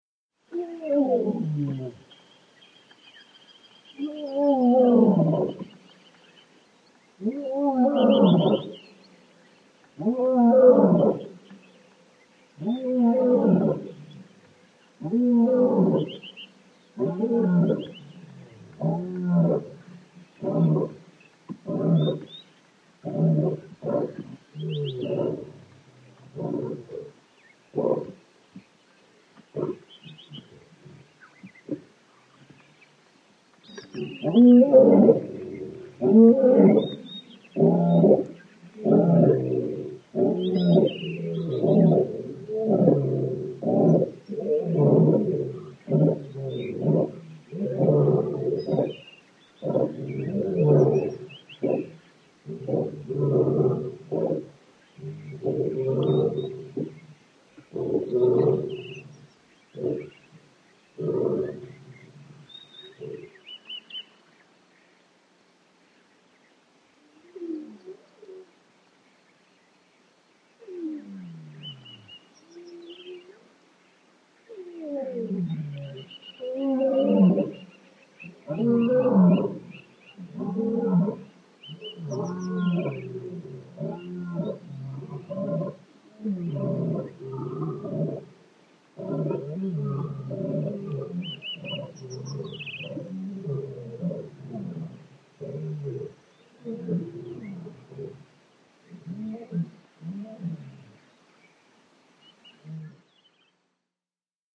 ANIMALS-BIRD BGS AFRICA: Plovers, Ibis calls, ducks, doves, lapping water in background, Zimbabwe. Zambezi banks atmosphere.